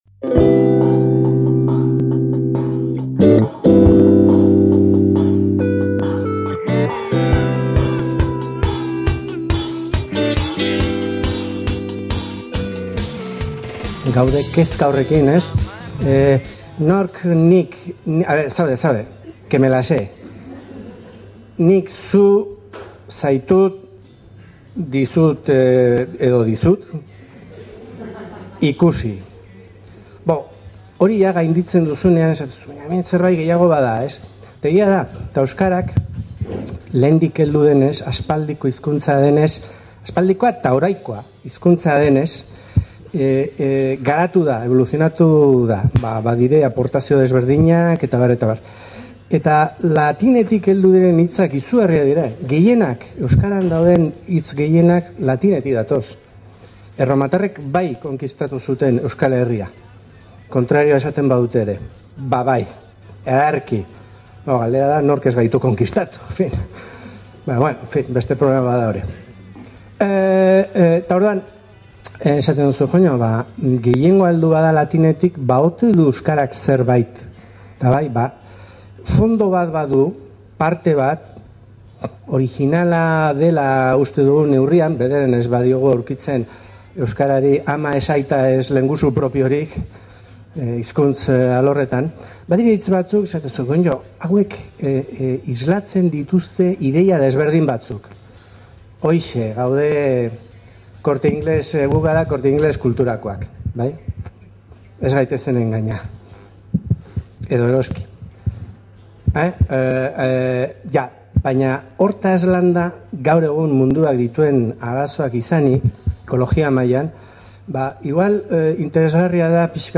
Donibane suen karietara, ospakizun hori udako solstizoaren erdigunean kokatu zuen ikertzaileak, haren esanaahiaren eta sinbologiaren xehetasunak emanez. Bilbo Hiria irratia bertan izan zen eta osorik grabatu zuen hitzaldia. Gaurko solasaldian hitzaldiaren hasierako minutuak eskaini dizkiegu gure entzuleei.